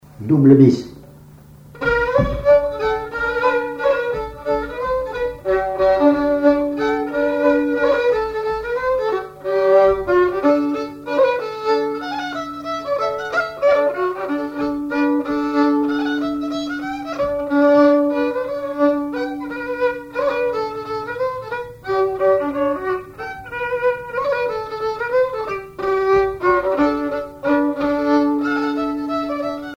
violoneux, violon,
danse : mazurka
instrumentaux au violon mélange de traditionnel et de variété
Pièce musicale inédite